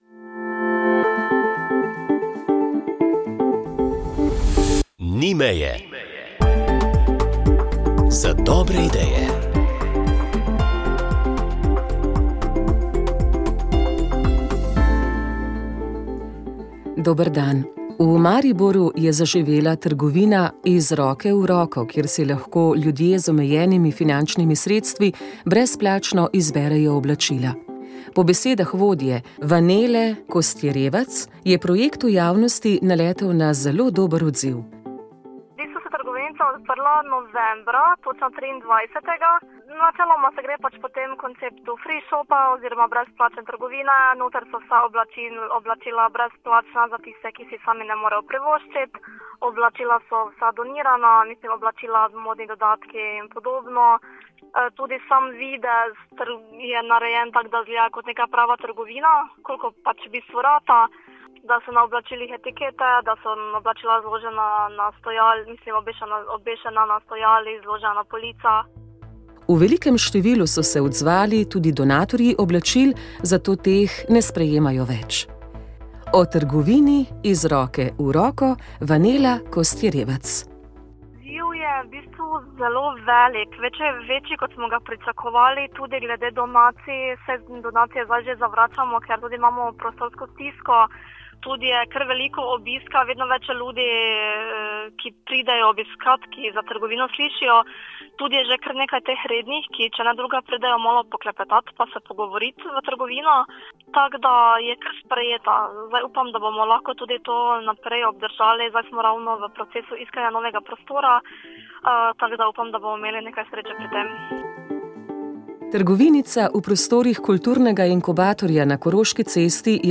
Rožni venec
Molili so radijski sodelavci.